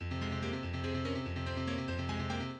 No. 9 F minor